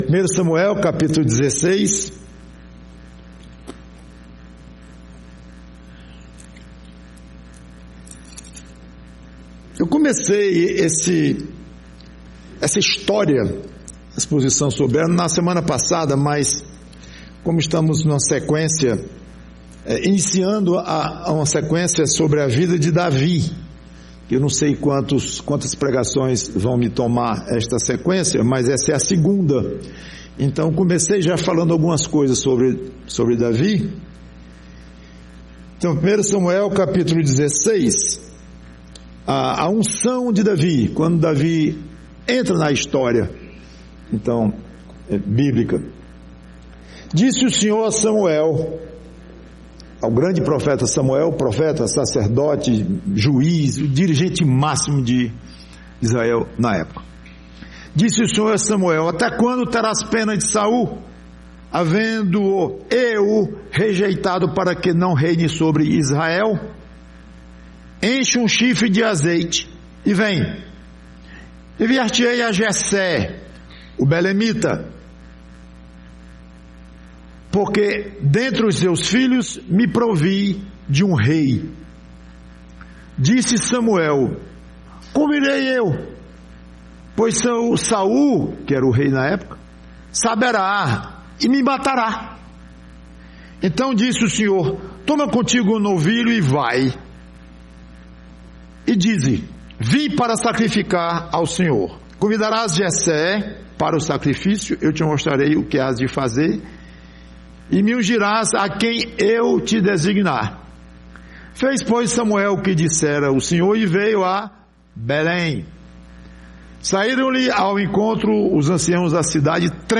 PREGAÇÃO Onde está Davi?